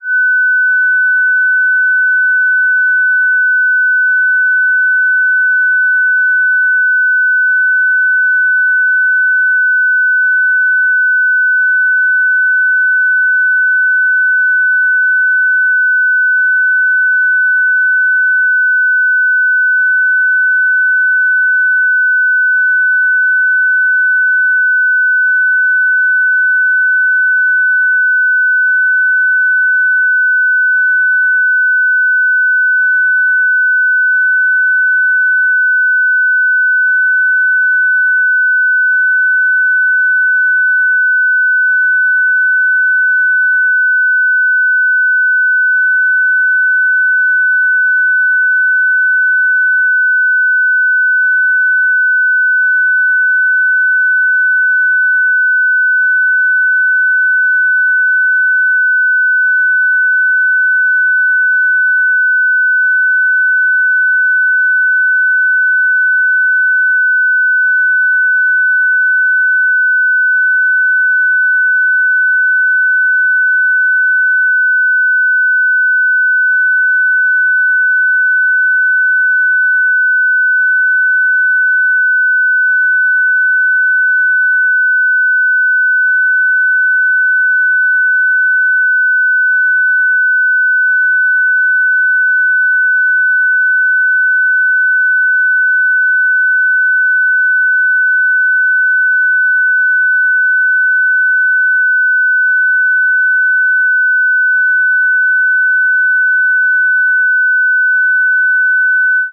FST4W-120.ogg